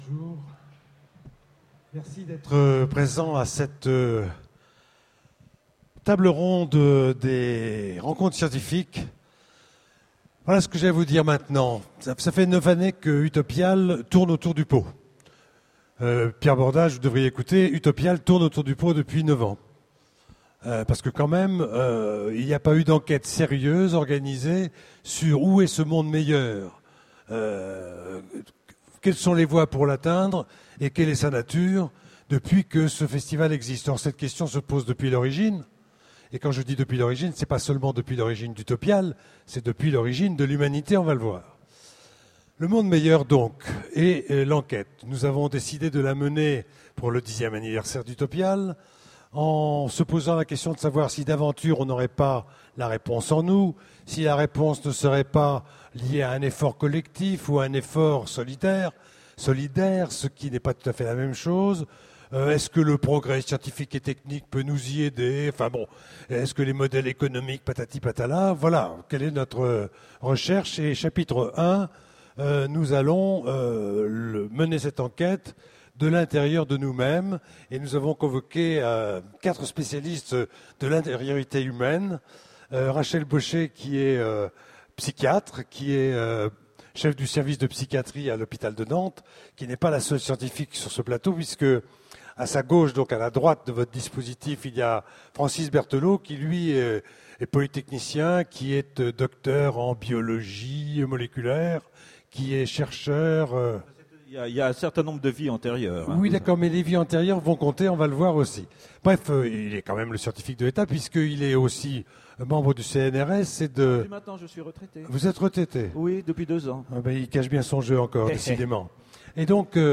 Utopiales 09 : Conférence Le monde meilleur est-il en nous ?